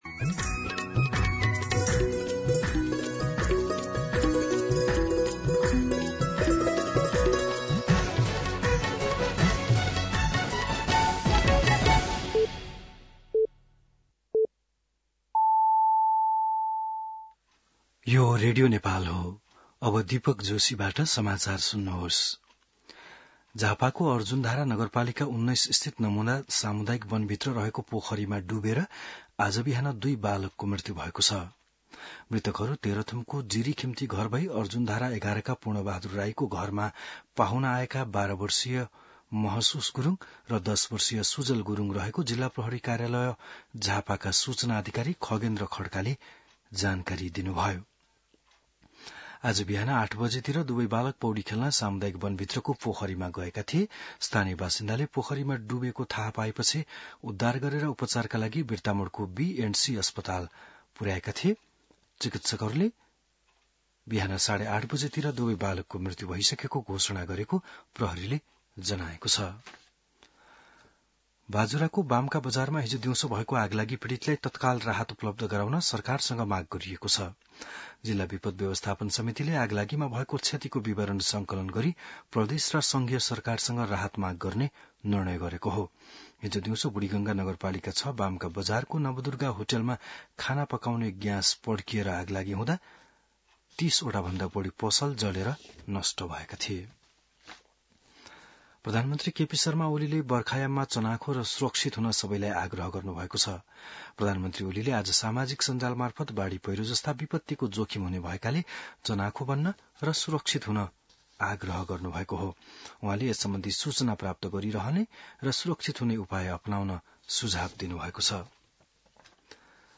बिहान ११ बजेको नेपाली समाचार : २४ जेठ , २०८२
11-am-Nepali-News.mp3